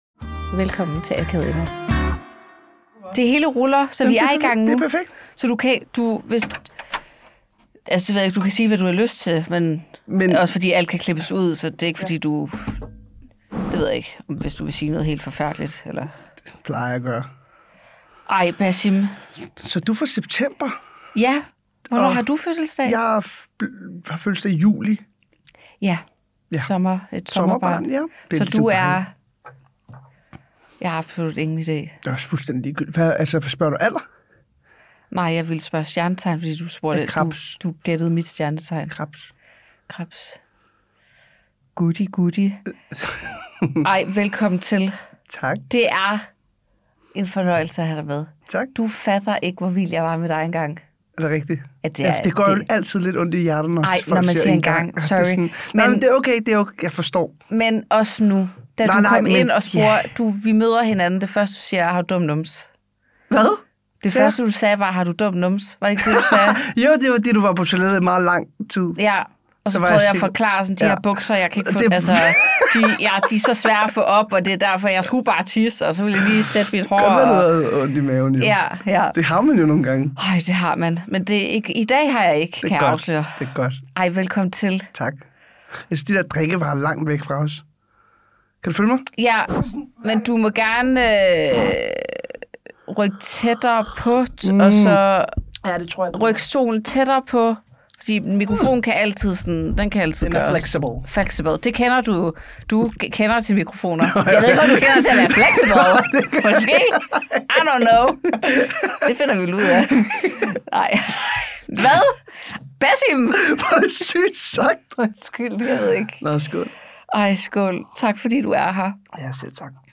Vi synger! Vi synger meget…. Vi snakker om X-factor, og om at skulle være bedre til at tage imod komplimenter og hvad Basim laver i dag.